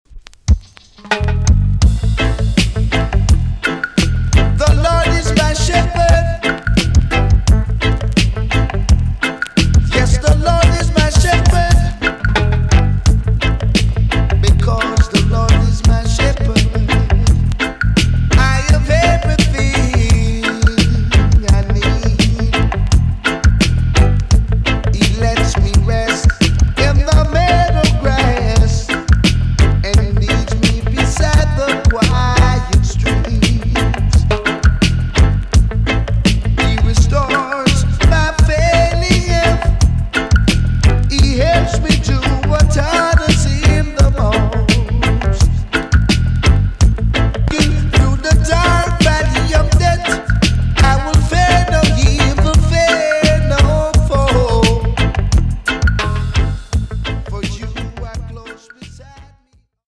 TOP >DISCO45 >80'S 90'S DANCEHALL
EX-~VG+ 少し軽いチリノイズが入ります。
B.SIDE NICE 渋VOCAL TUNE!!